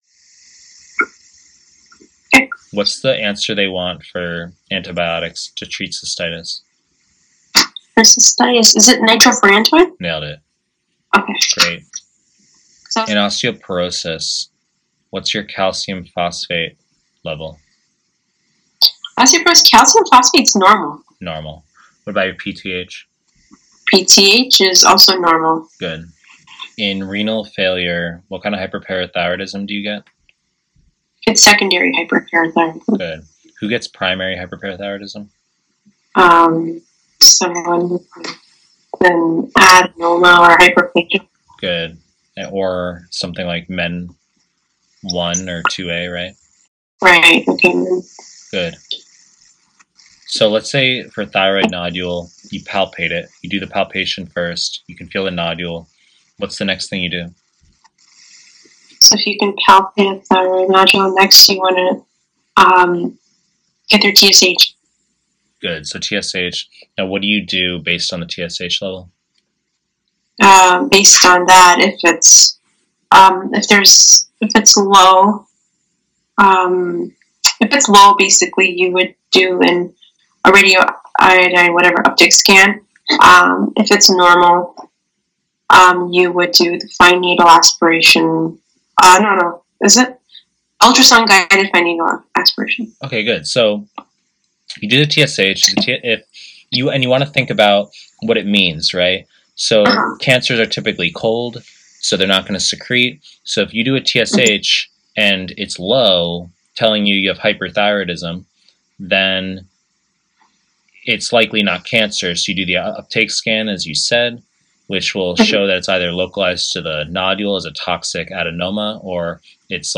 Pre-recorded lectures